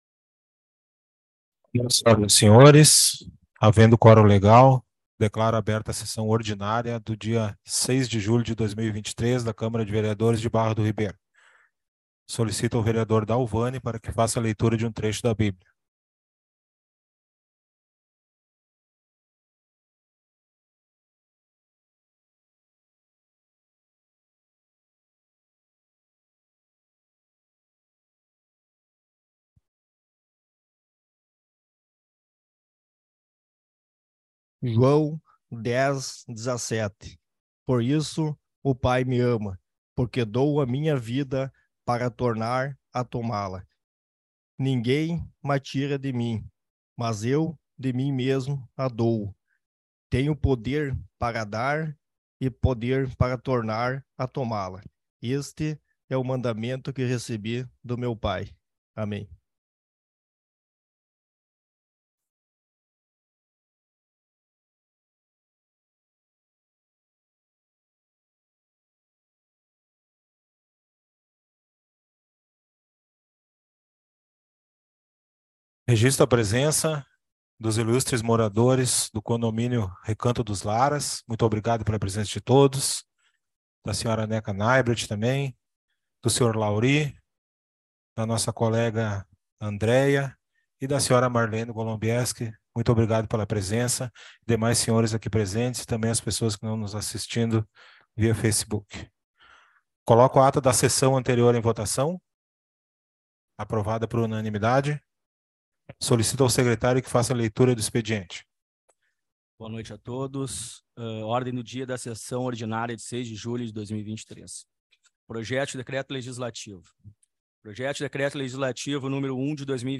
Sessão Ordinária 06.07.2023